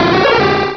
pokeemerald / sound / direct_sound_samples / cries / ampharos.aif
-Replaced the Gen. 1 to 3 cries with BW2 rips.